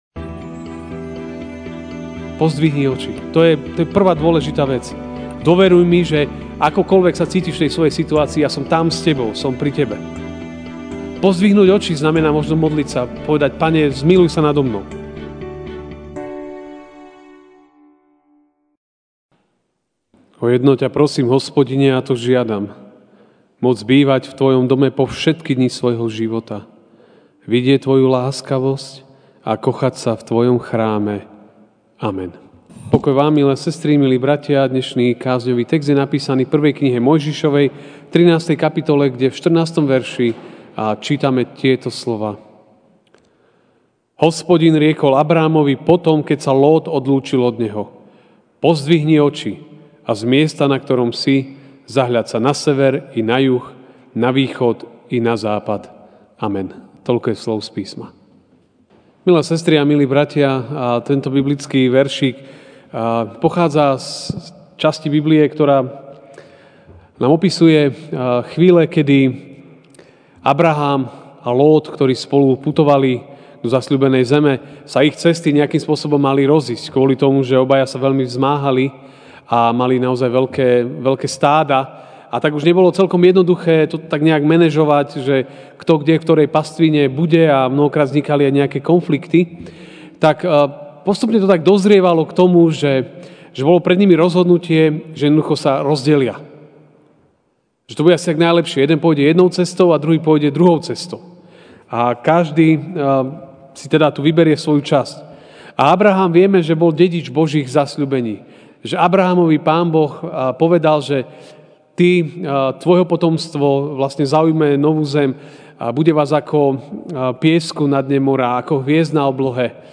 aug 04, 2019 Pozdvihni oči MP3 SUBSCRIBE on iTunes(Podcast) Notes Sermons in this Series Večerná kázeň: Pozdvihni oči (1M 13, 14) Hospodin riekol Abrámovi potom, keď sa Lót odlúčil od neho: Pozdvihni oči a z miesta, na ktorom si, zahľaď sa na sever i na juh, na východ i na západ.